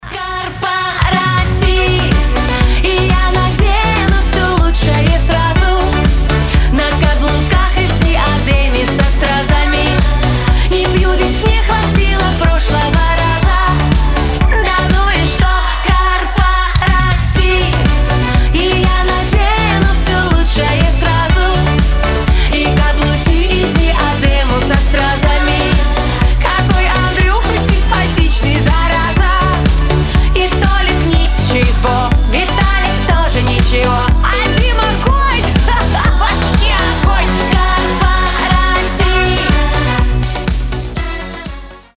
• Качество: 320, Stereo
поп
женский вокал
заводные
праздничные
озорные